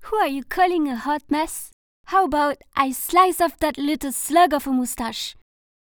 French accent, alsacien
I am a French singer and voice-over actress with a youthful, lively, and dynamic voice.